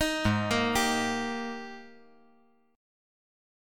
AbM7sus2 Chord
Listen to AbM7sus2 strummed